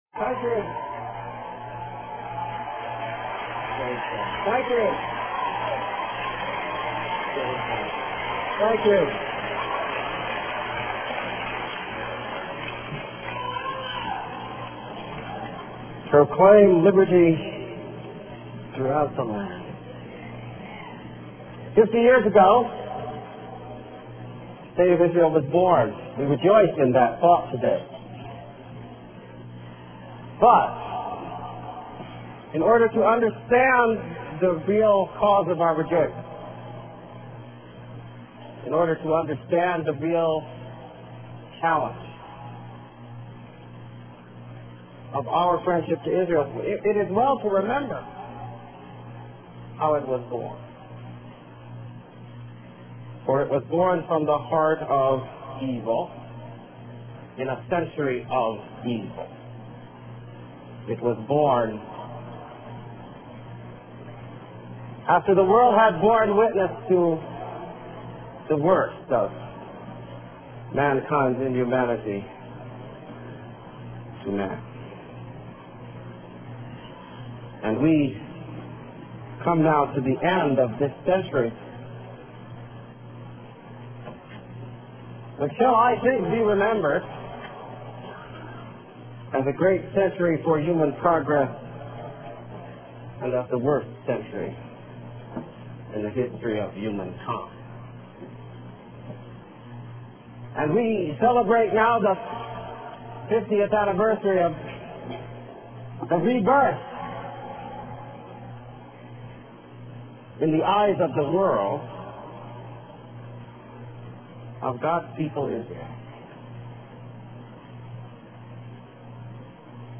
Keynote address, "Rebuilding the Wall," Israel's Jubilee :: Alan Keyes Archives